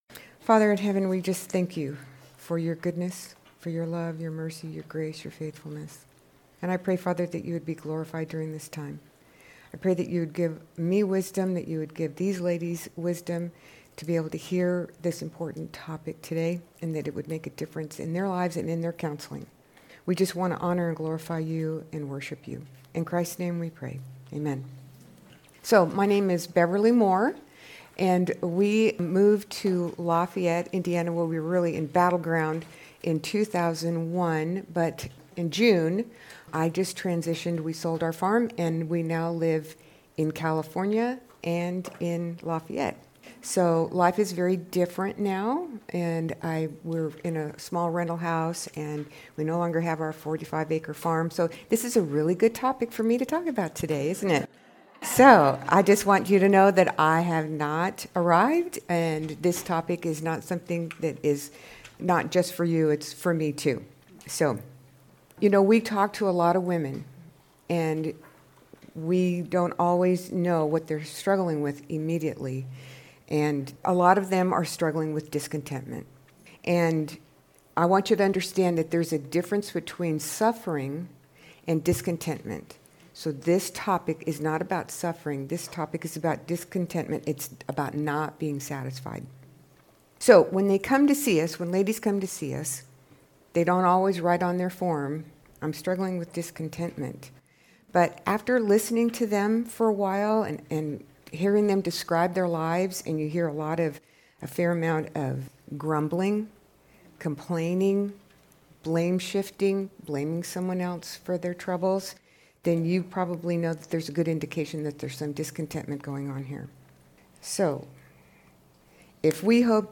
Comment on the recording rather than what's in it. This is a session from the Biblical Counseling Training Conference hosted by Faith Church in Lafayette, Indiana. You may listen to the first 10 minutes of this session by clicking on the "Preview Excerpt" button above.